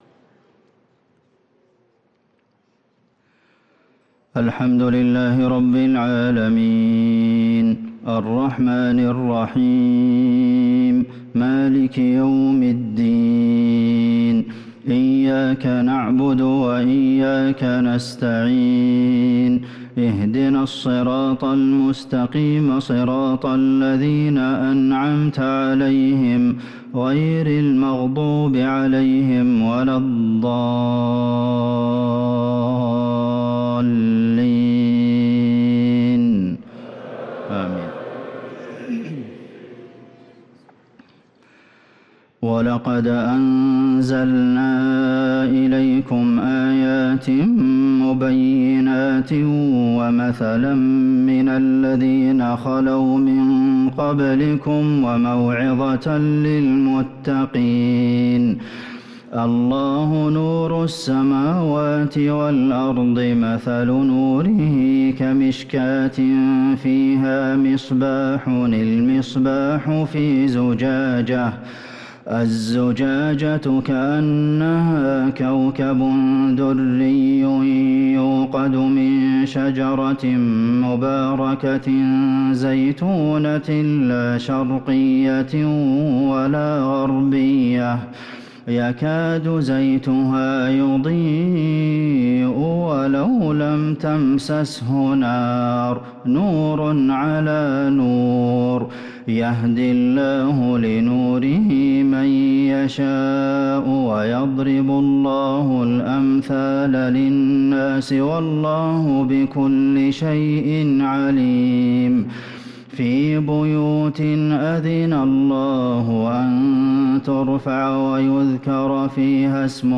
صلاة العشاء للقارئ عبدالمحسن القاسم 15 محرم 1445 هـ
تِلَاوَات الْحَرَمَيْن .